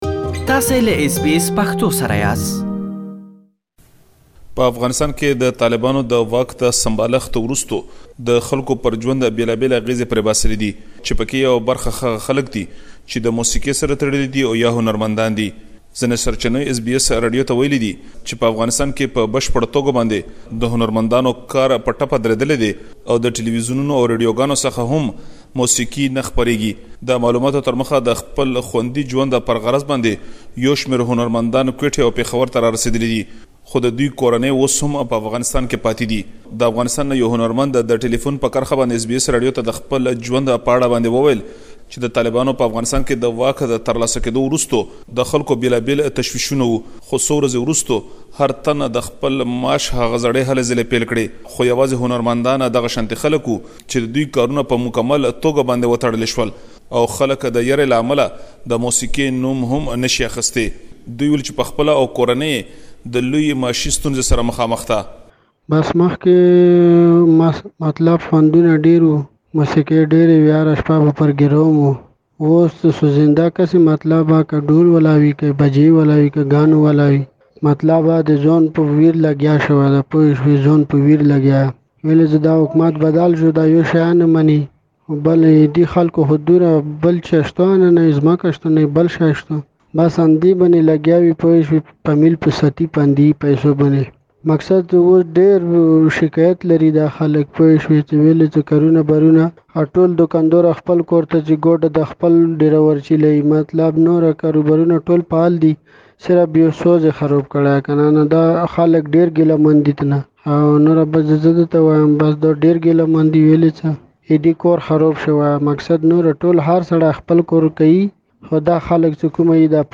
تاسو ته مو پدې اړه يو رپوت برابر کړی چې دلته يې اوريدلی شئ.